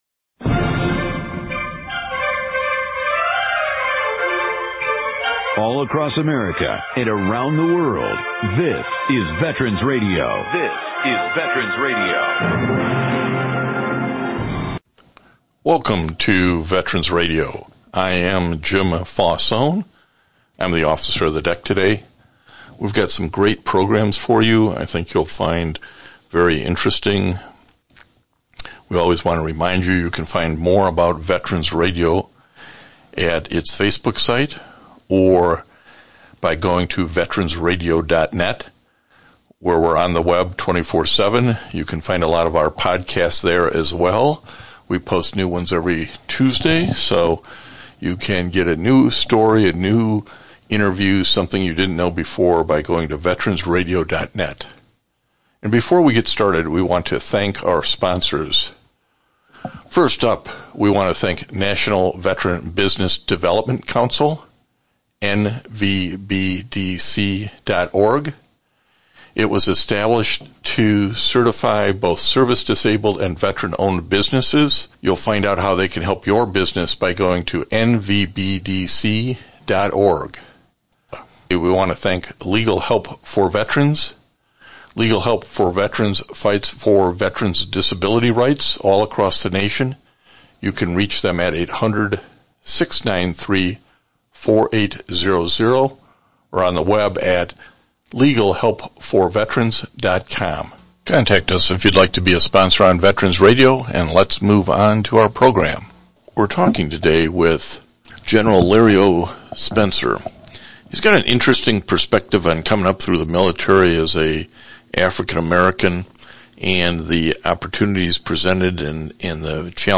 February 13, 2022 This week’s one hour radio broadcast is pre-recorded.